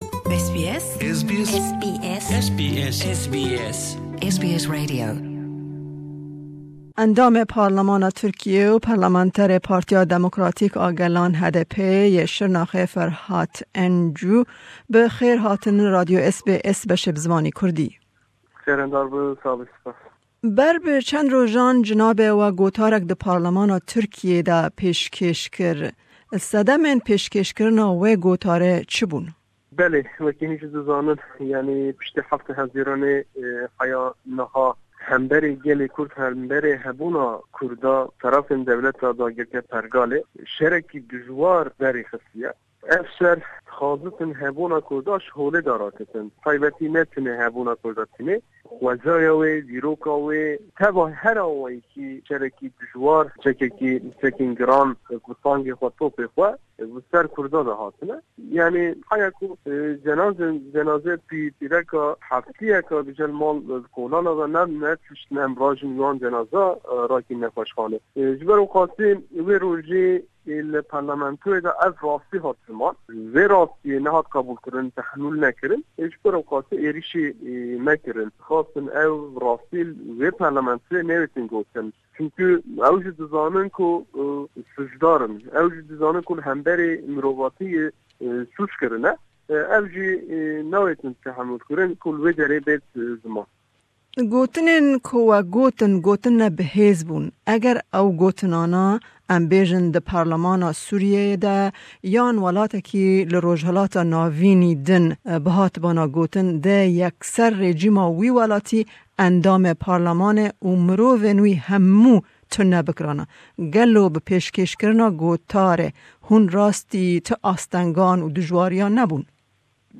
Me hevpeyvînek bi endamê Parlemana Tirkiyê Ferhat Encu re derbarî gotara ku wî di parlemanê de pêshkêsh kirî pêk anî, ku ew gotar bû sedema pevçûnekê di nav parlemanê de. Me herweha li ser rewsha Tirkiyê û siberoja sherê di navberî hêza Tirk û shervanên kurd de jî ji berêz Encû pirsî.